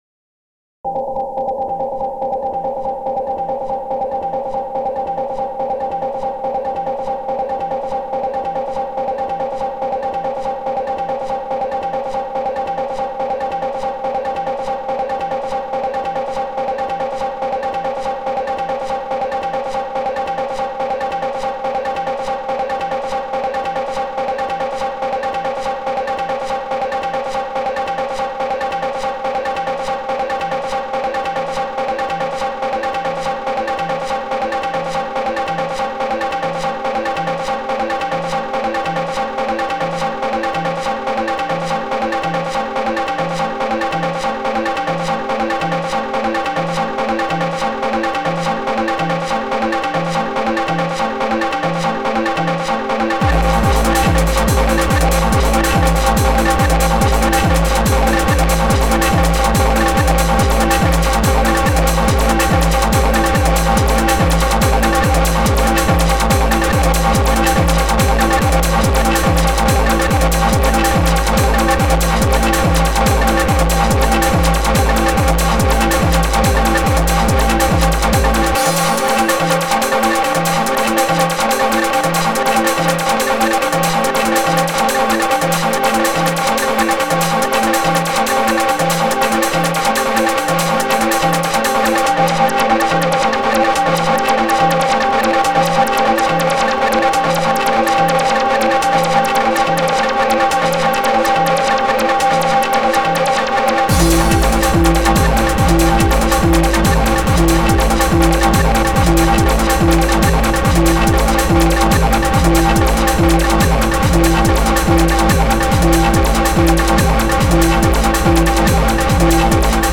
Extended Module  |  1998-01-26  |  272KB  |  2 channels  |  44,100 sample rate  |  3 minutes, 53 seconds
xm (FastTracker 2 v1.04)
all loops are 100%